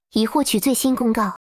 announcement_display.wav